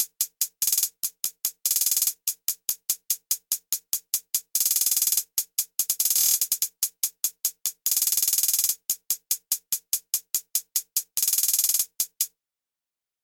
描述：陷阱和科幻的结合。沉重的打击和神秘感。使用这些循环来制作一个超出这个世界范围的爆炸性节目吧 :)D小调
Tag: 145 bpm Trap Loops Percussion Loops 2.23 MB wav Key : D